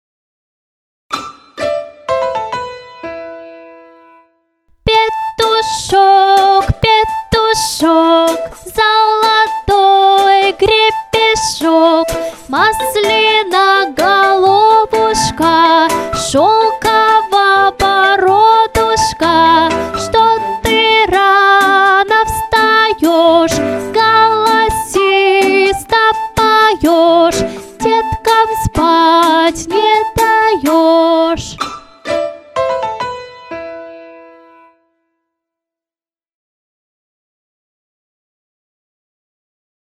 Музыкально-ритмическое упражнение на известную русскую народную потешку